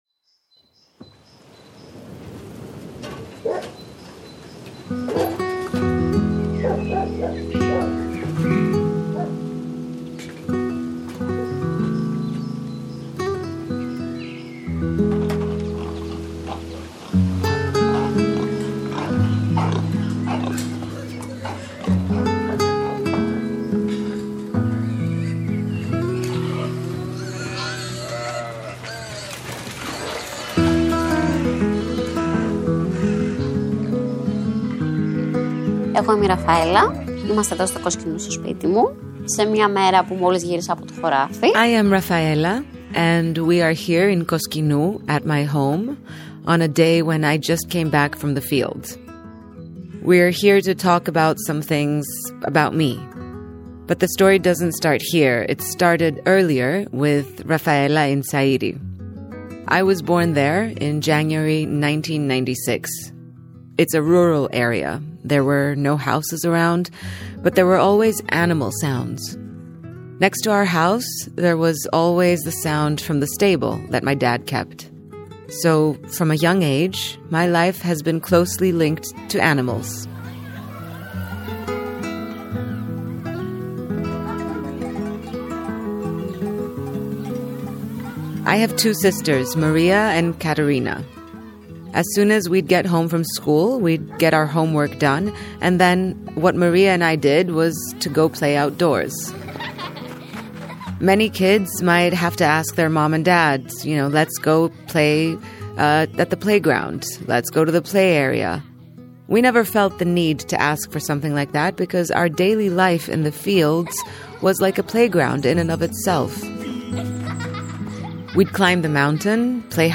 Voiceover: Melia Kreiling